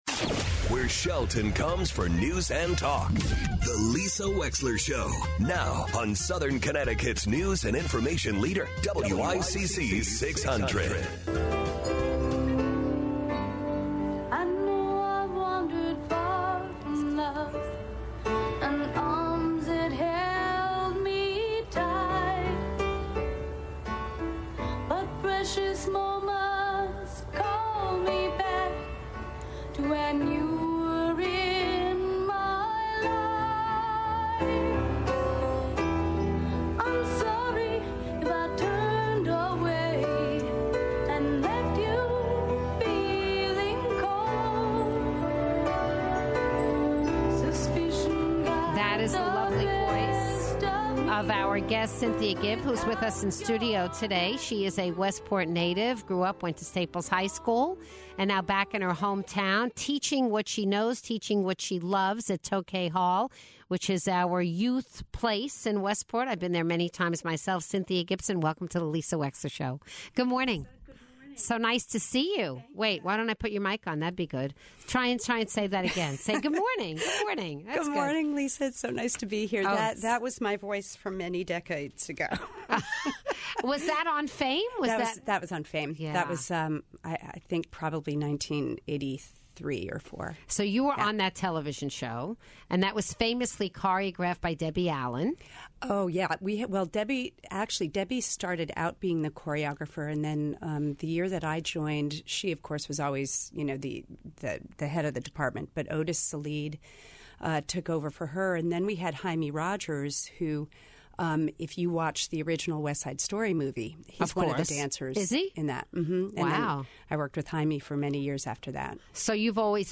Cynthia Gibb is in studio to talk about vocal and acting lessons.